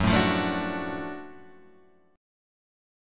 F#mM7#5 chord {2 0 0 2 3 1} chord